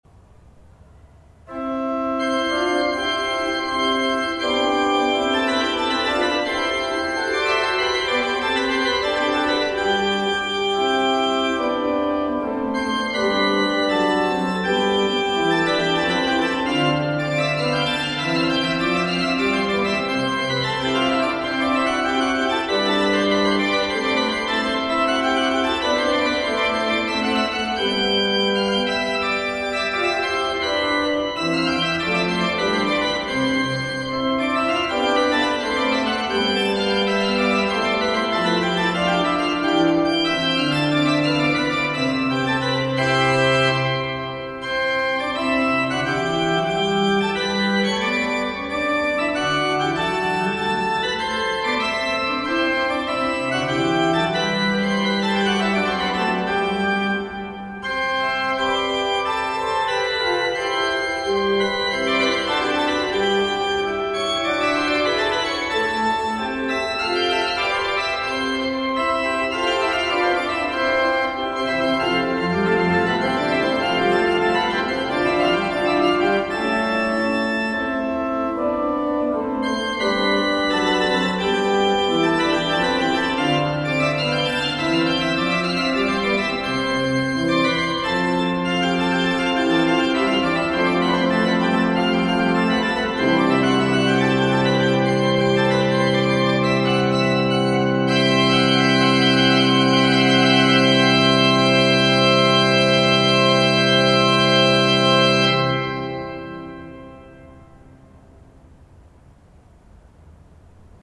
– Choral